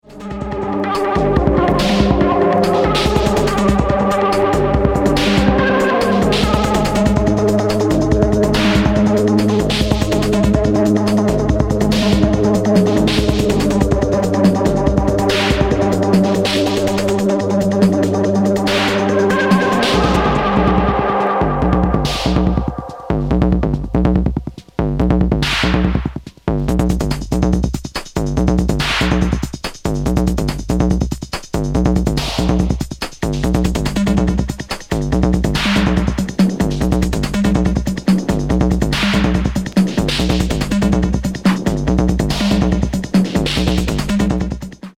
Ice cold masculine electro tracks
darker robotic electro with vocoder vox
Electro